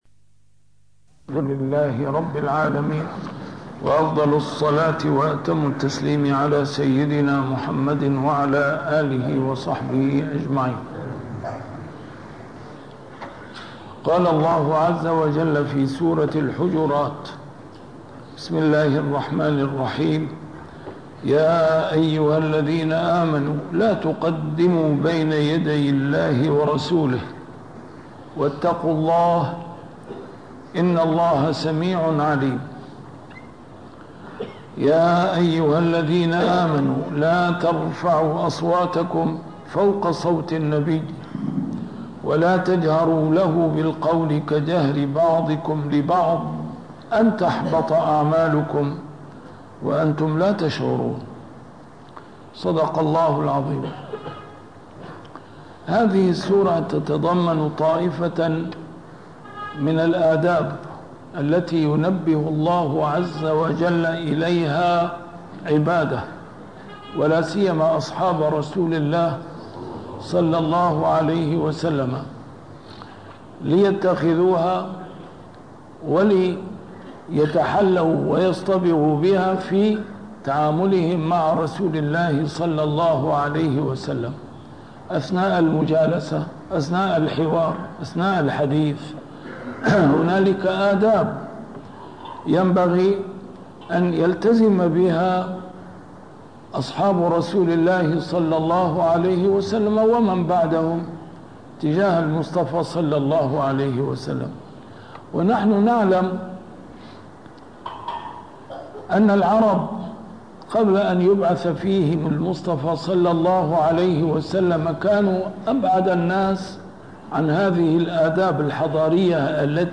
A MARTYR SCHOLAR: IMAM MUHAMMAD SAEED RAMADAN AL-BOUTI - الدروس العلمية - تفسير القرآن الكريم - تسجيل قديم - الدرس 657: الحجرات 01-03